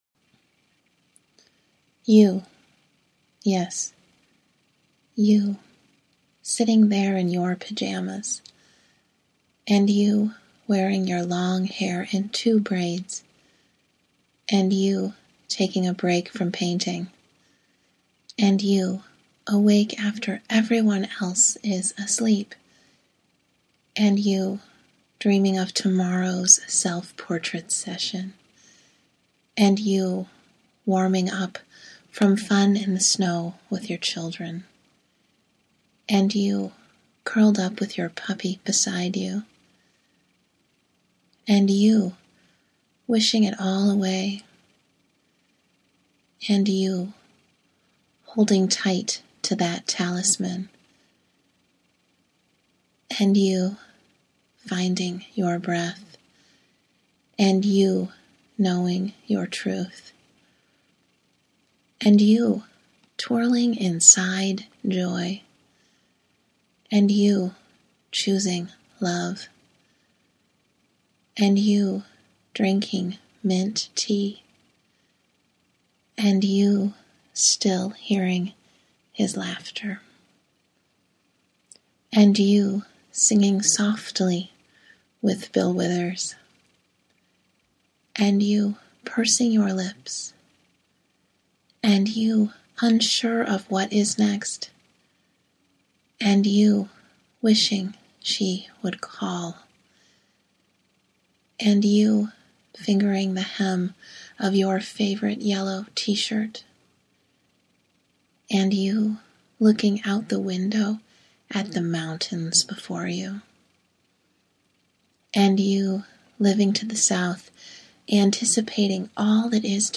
In the short audio, I share a few thoughts, read "A Morning Offering" by John O'Donohue from To Bless the Space Between Us, and share a chant.